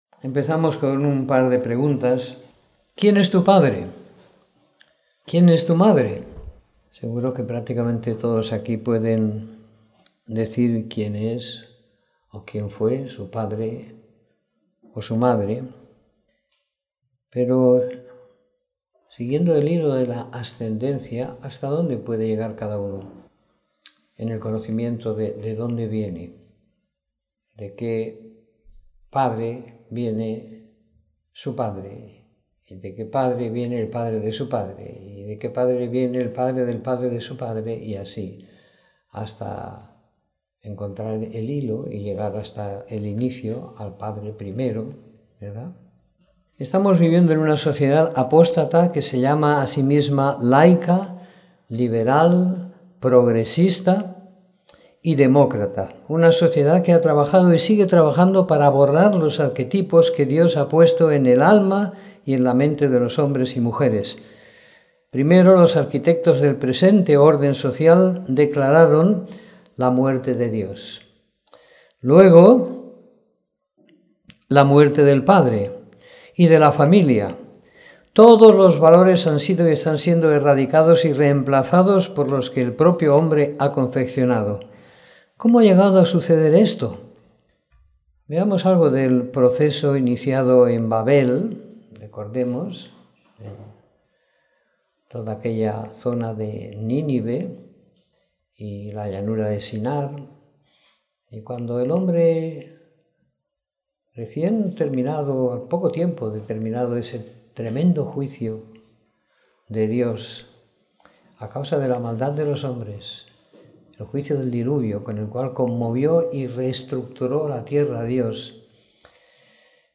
Domingo por la Mañana . 12 de Junio de 2016